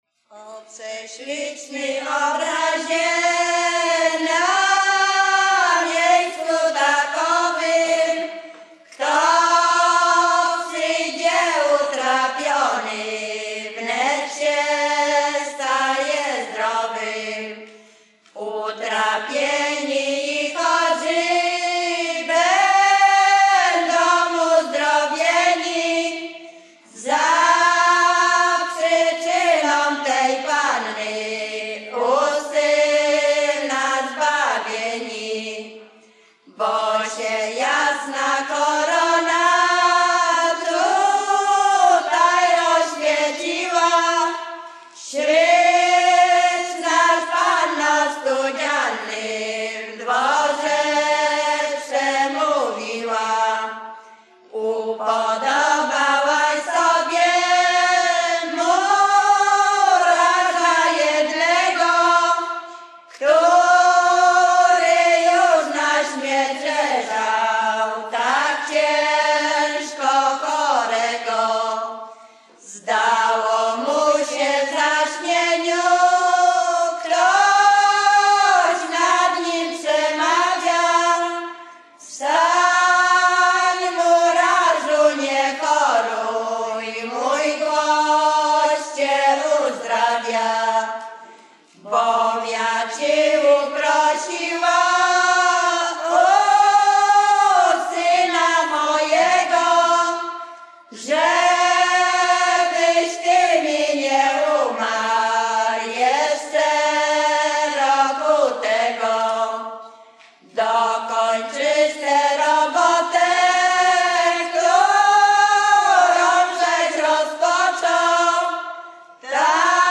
Zespół śpiewaczy z Gałek
województwo mazowieckie, powiat przysuski, gmina Rusinów, wieś Gałki Rusinowskie
Pielgrzymkowa
katolickie nabożne pielgrzymkowe dziadowskie